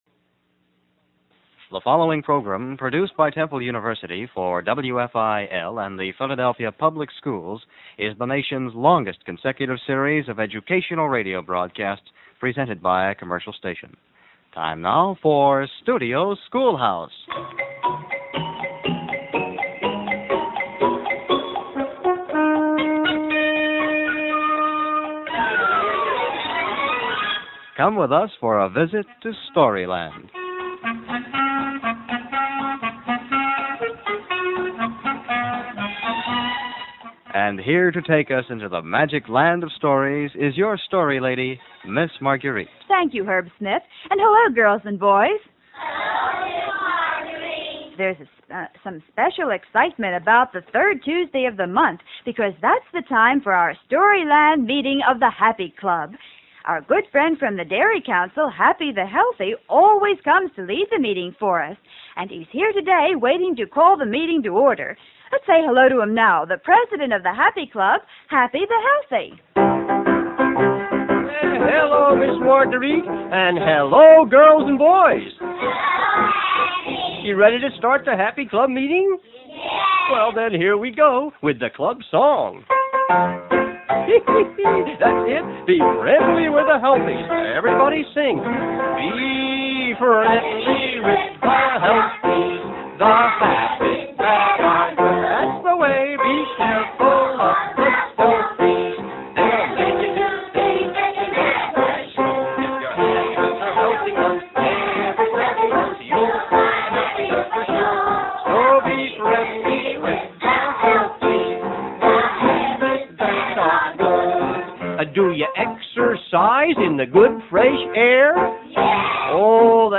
The shows were basically live-to-tape.
We always got a kick out of this show, because the 3rd and 4th graders from the local schools would come in to sing the club song and take the club pledge.
These shows were always taped in Studio B because we used the piano.